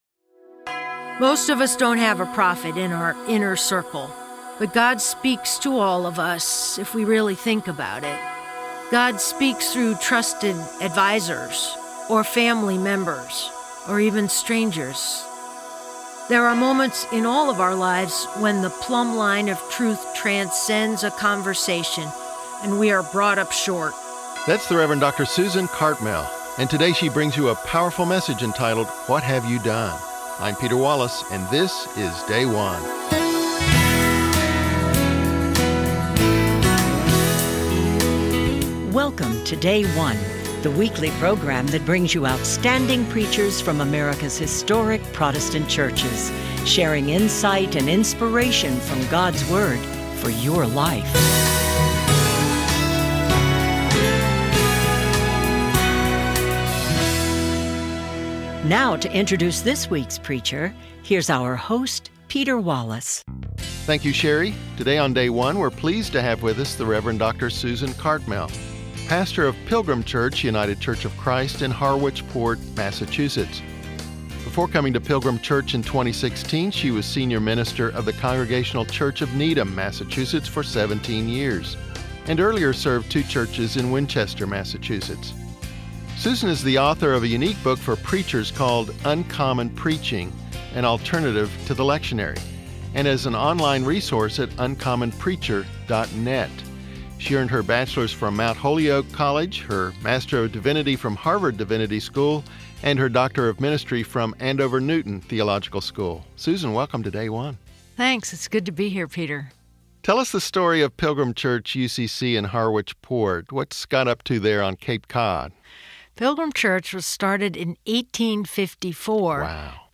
United Church of Christ 11th Sunday after Pentecost - Year B 2 Samuel 11:26--12:10